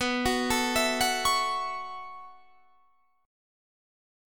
B7sus2sus4 Chord